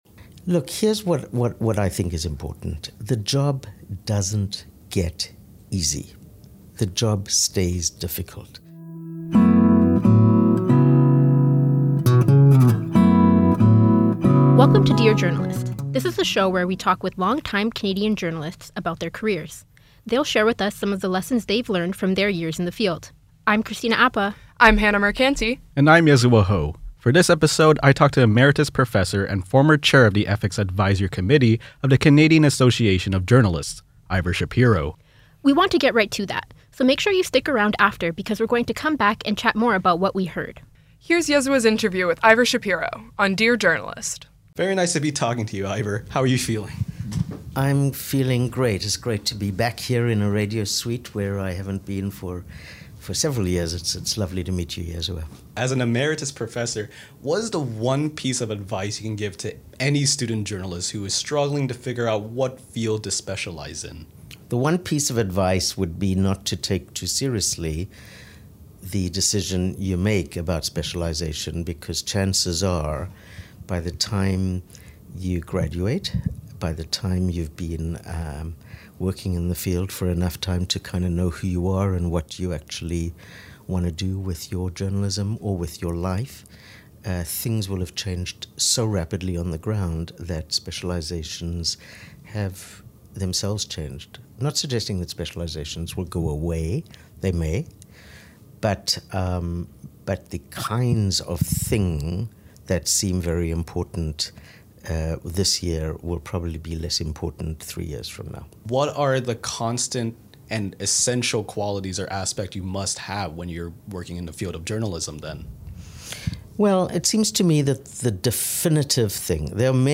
DISCLAIMER: The interview in this episode took place in October 2023